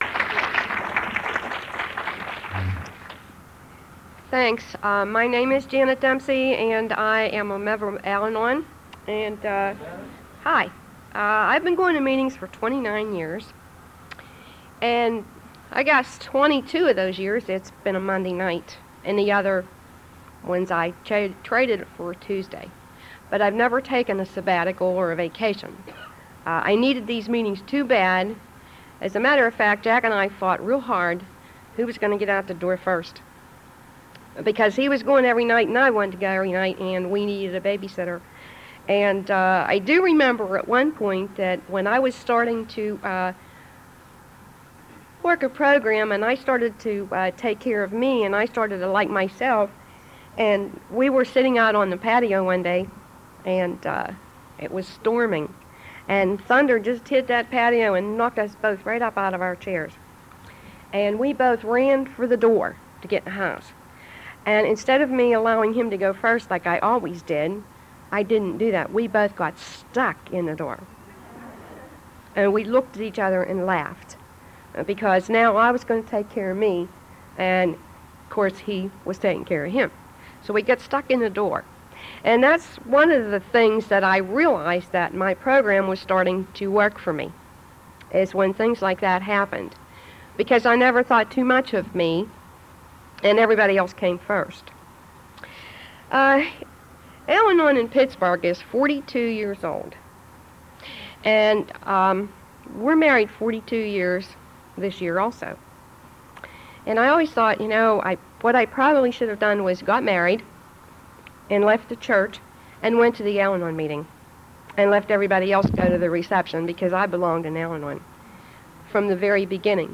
4th Miss. Valley Regional Conference &#8211